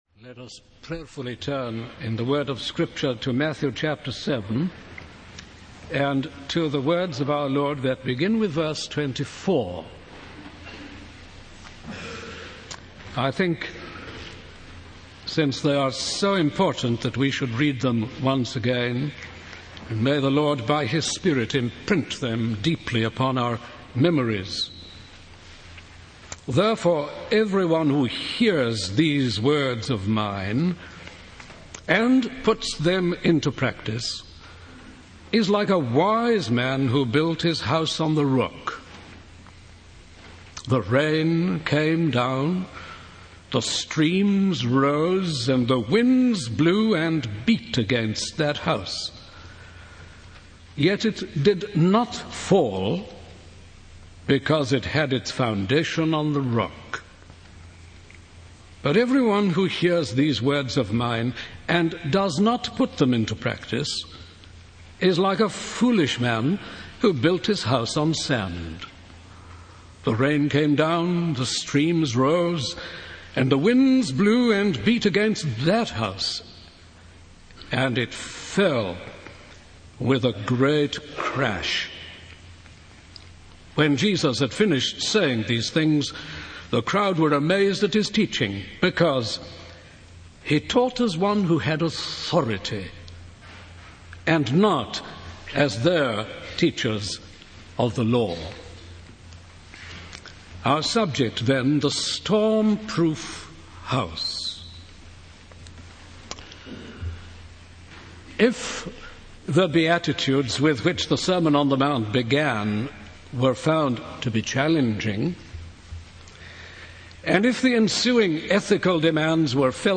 In this sermon, the preacher focuses on the words of Jesus in Matthew 7:24-27. He emphasizes the importance of not only hearing the words of Jesus, but also putting them into practice. The preacher highlights the distinction between the wise and foolish builders, who may appear similar but are actually dissimilar in their actions.